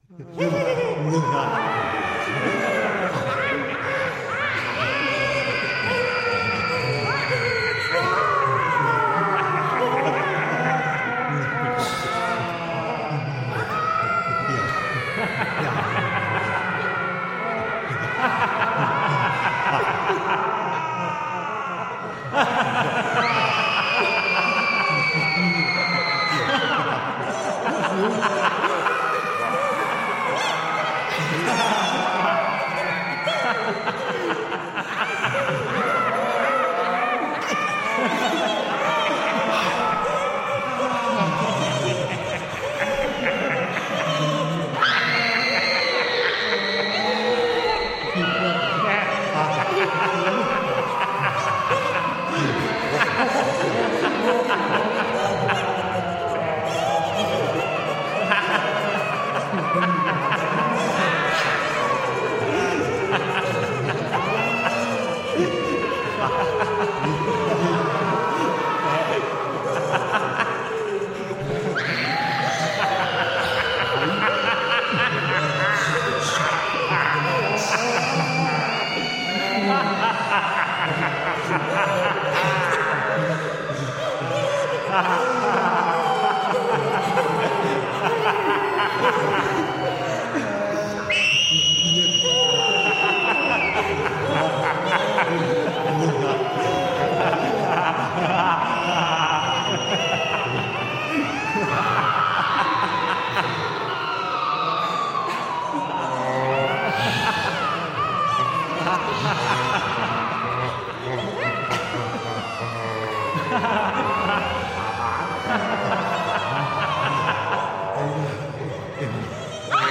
Эта страница предлагает коллекцию звуков, воссоздающих атмосферу психиатрической больницы: отдаленные голоса, шаги по пустым коридорам, металлический лязг решеток.
Атмосферный шум дурдома